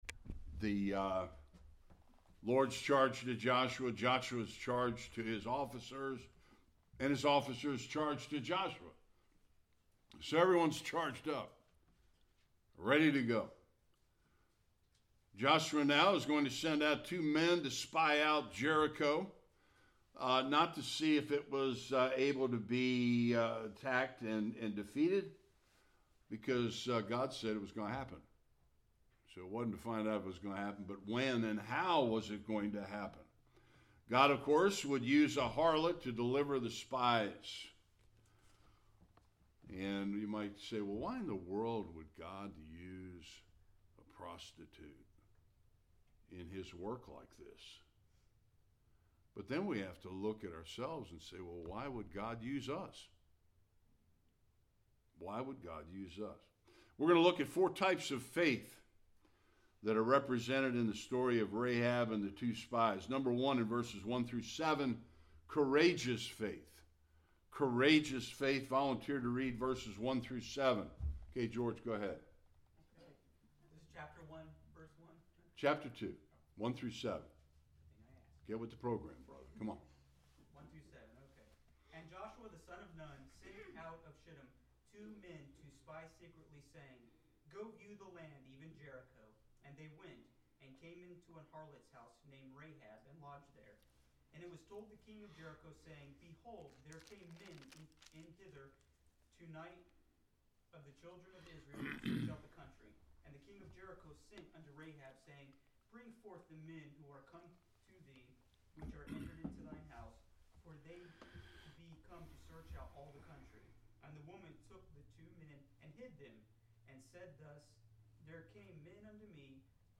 1-24 Service Type: Sunday School We see in Rahab 4 types of faith displayed in the deliverance of the 2 spies.